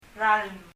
日本人には苦手な r音 と l音 が両方入ってます。
« balloon 風船 year 年 » water 水 ralm [rʌlm] 「水」です。